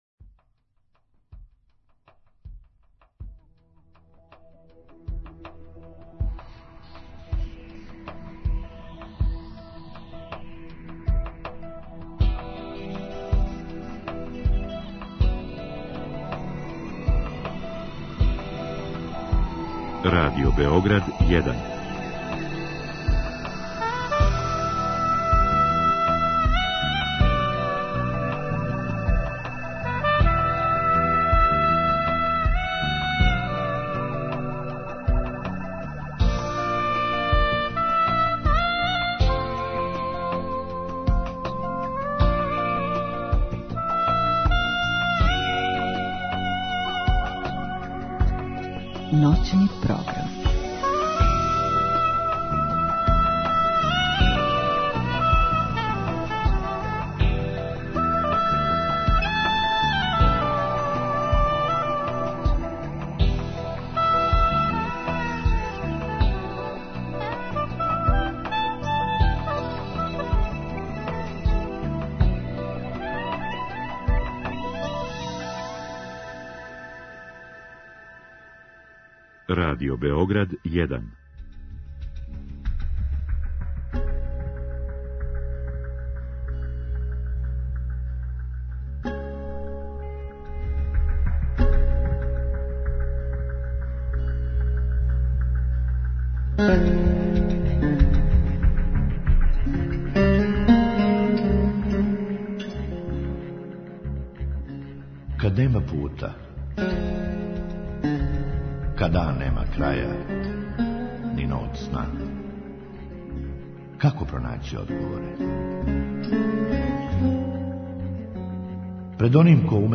У другом сату, слушаоци у директном програму могу поставити питање гошћи у вези са темом.